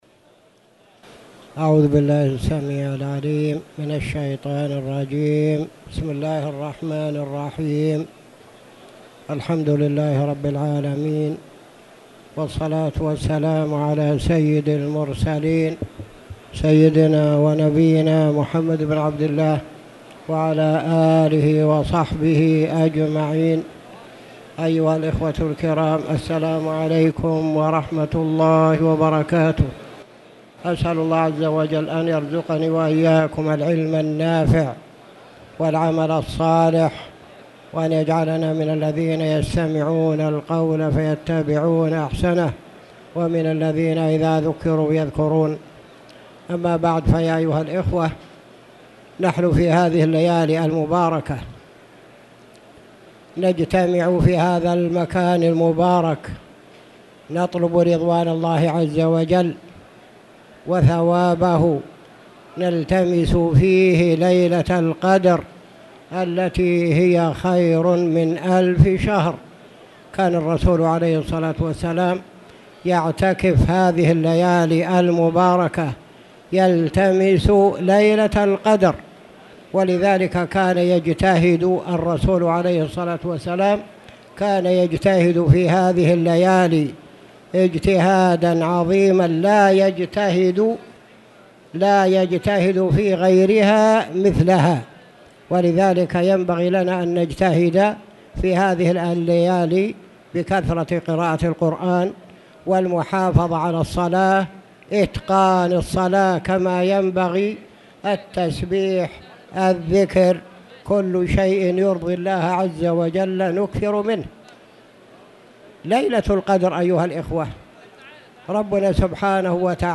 تاريخ النشر ٢١ رمضان ١٤٣٧ هـ المكان: المسجد الحرام الشيخ